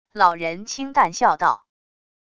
老人轻淡笑道wav音频